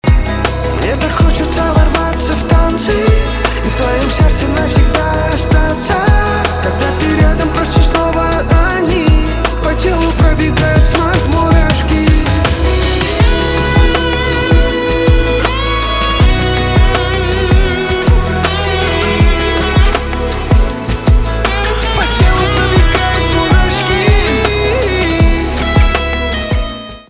поп
битовые , гитара , чувственные
романтические